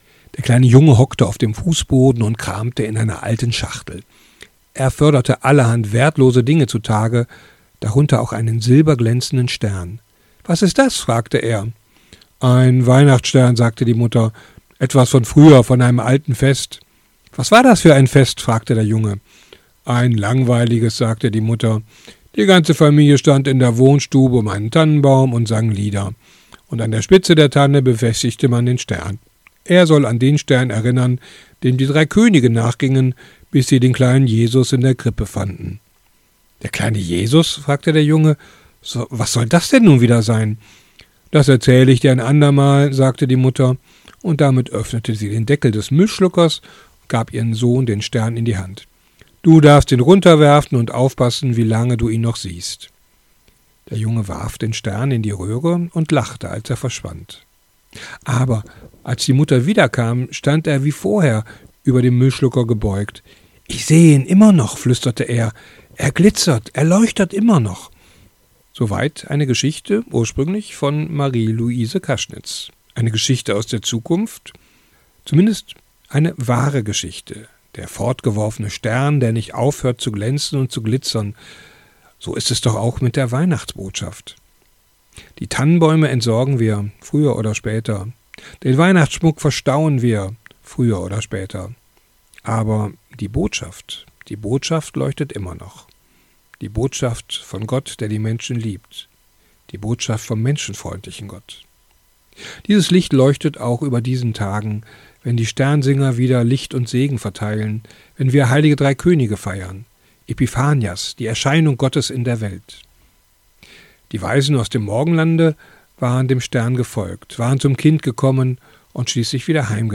Radioandacht vom 3. Januar